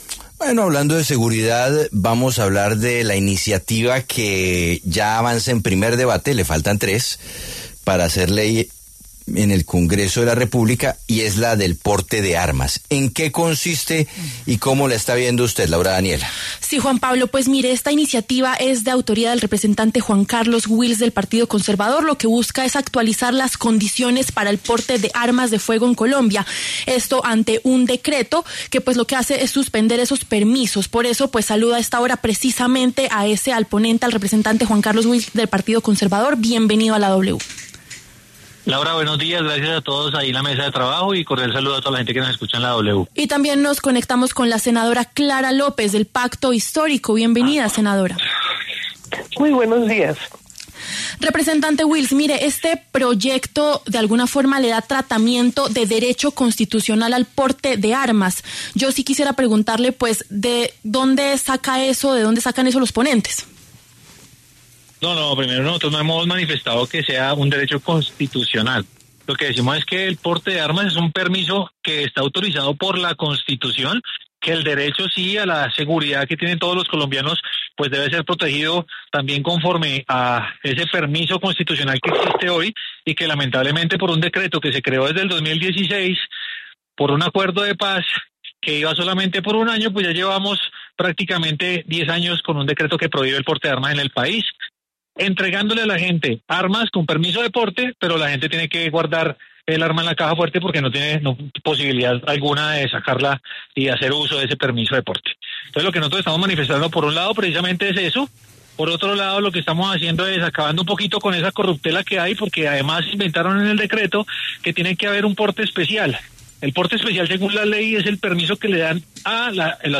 El representante conservador Juan Carlos Wills, ponente del proyecto y uno de sus principales promotores, así como la senadora Clara López, del Pacto Histórico, hablaron en La W.
Debate: ¿qué dicen los congresistas sobre el proyecto que flexibiliza el porte de armas en Colombia?